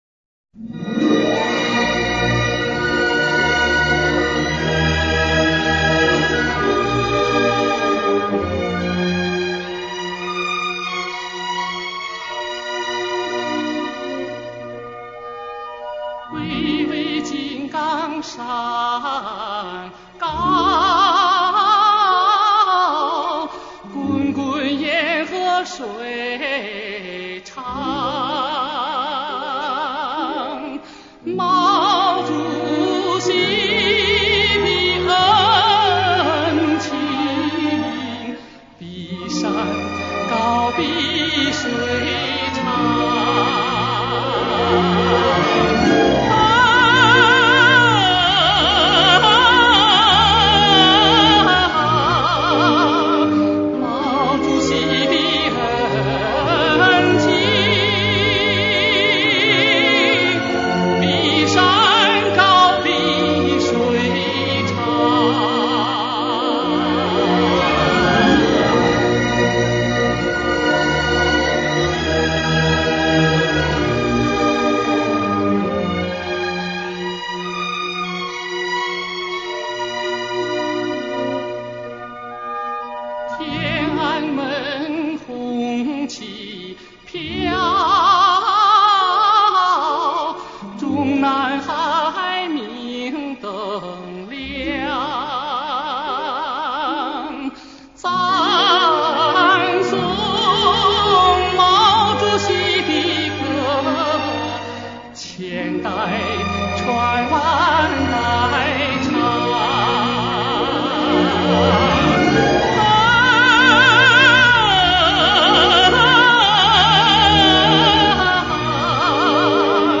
基本选取的是历史录音
演唱热情奔放，富有乐感，吐字清晰，声音流畅，高音稳定、透明，辉煌而华丽。